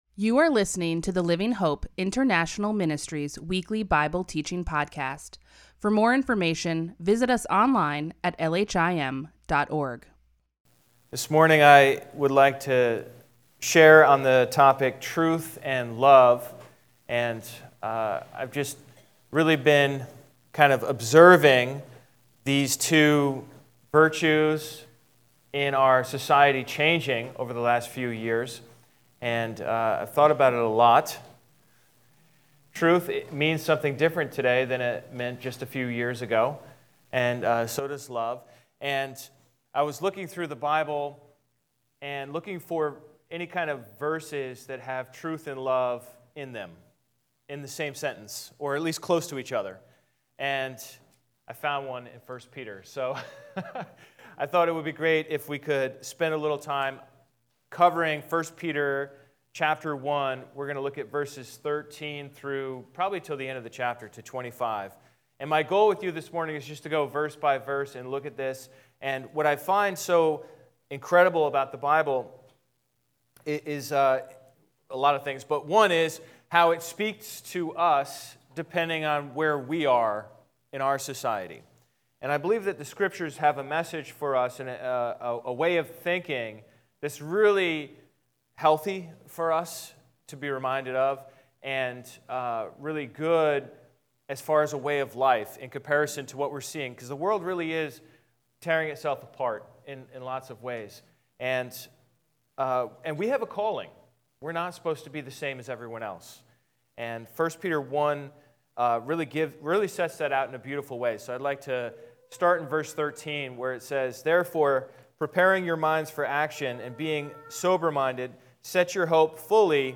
LHIM Weekly Bible Teaching